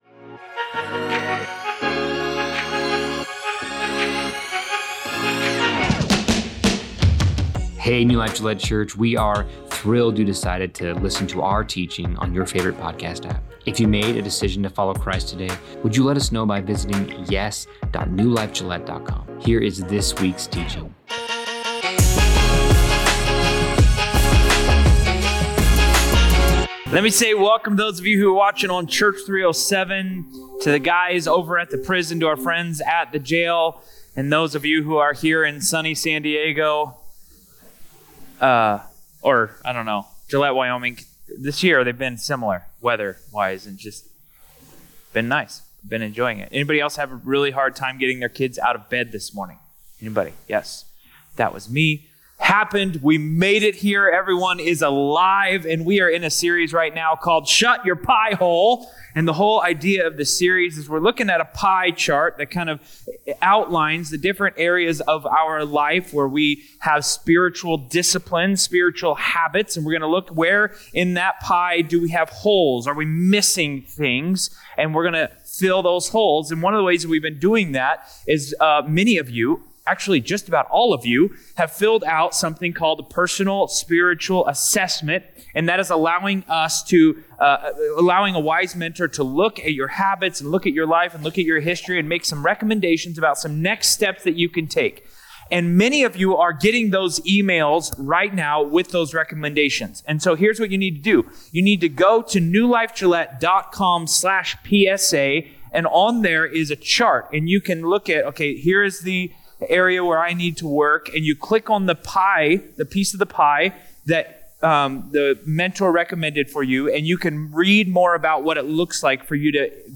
New Life Gillette Church Teachings